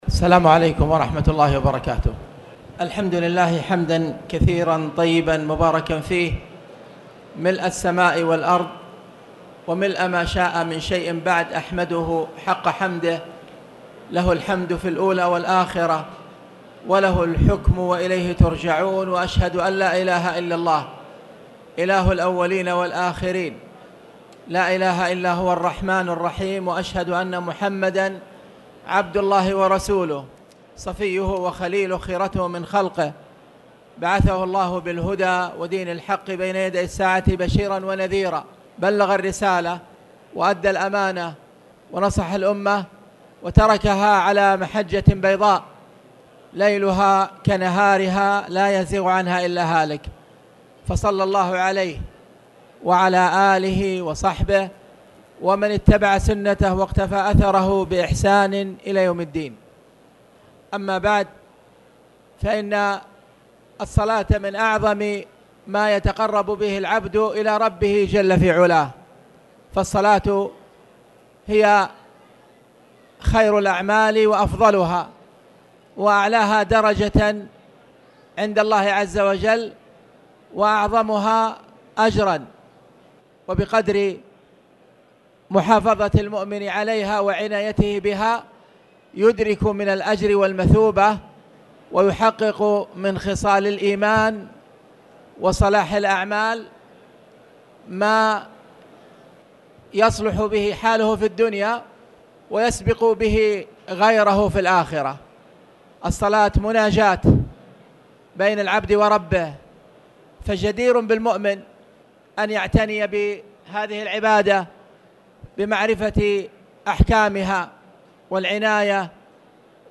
تاريخ النشر ١ شعبان ١٤٣٨ هـ المكان: المسجد الحرام الشيخ